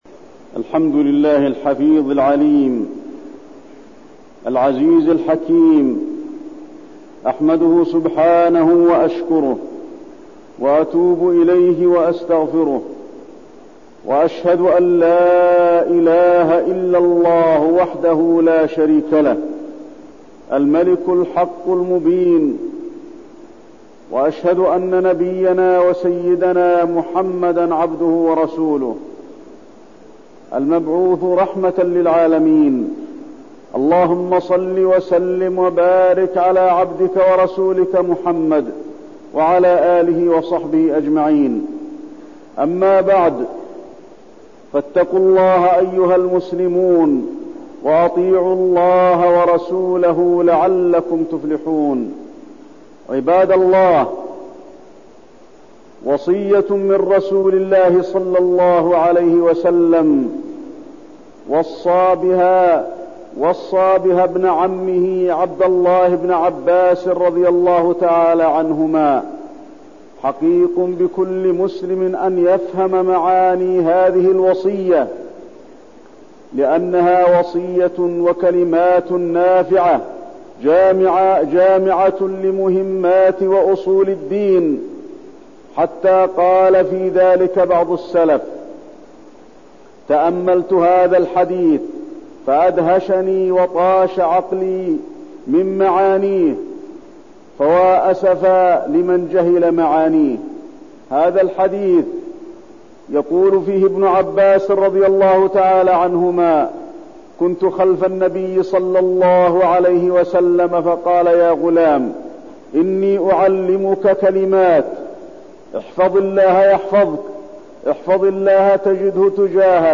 تاريخ النشر ١ صفر ١٤١٠ هـ المكان: المسجد النبوي الشيخ: فضيلة الشيخ د. علي بن عبدالرحمن الحذيفي فضيلة الشيخ د. علي بن عبدالرحمن الحذيفي وصية النبي صلى الله عليه وسلم لابن عباس